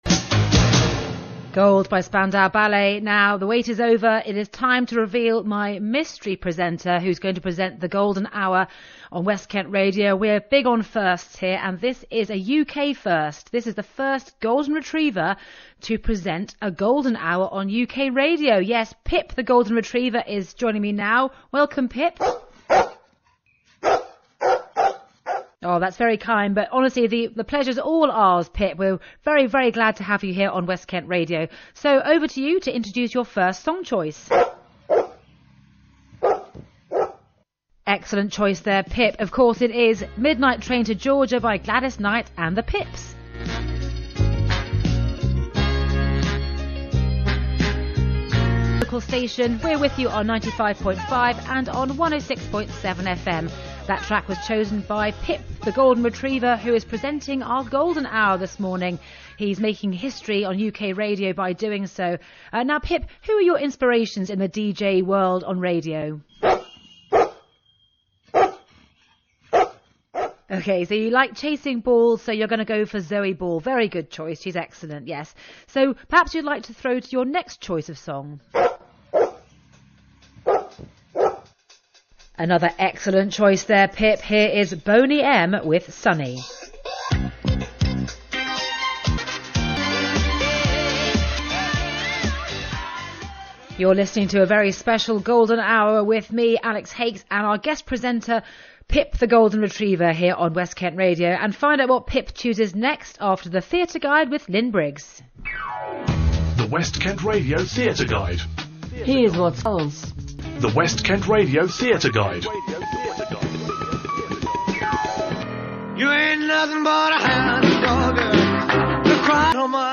Pip the Golden Retriever - LIVE On-air
Pip, the golden retriever visited the West Kent Radio studio to present the 'Canine Golden Hour'.